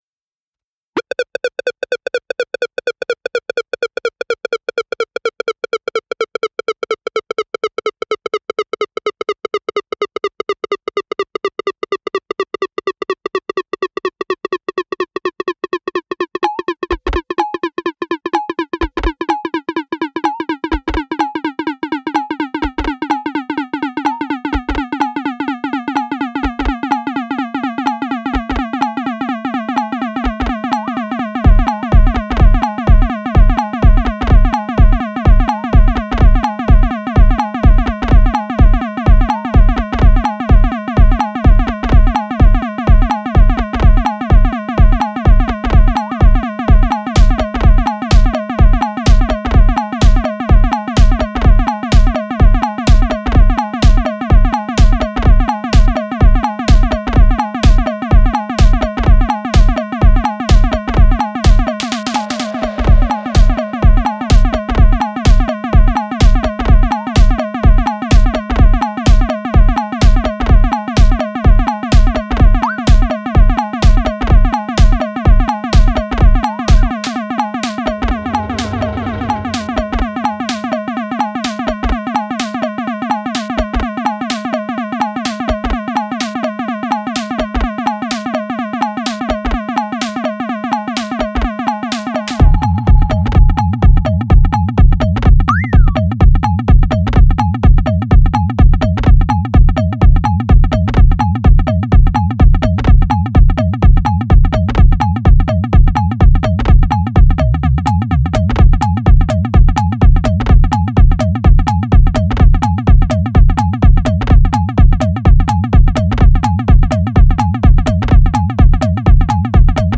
A very fresh and hypnotizing techno release